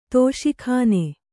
♪ tōṣi khāne